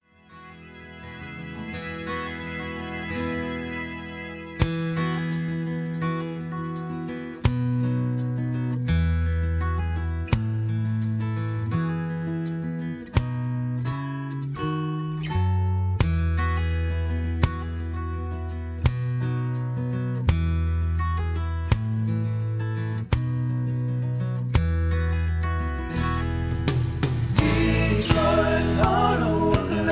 backing tracks, karaoke, sound tracks, studio tracks, rock